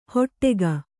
♪ hoṭṭega